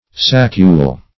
Saccule \Sac"cule\, n. [L. sacculus, dim. of saccus sack.]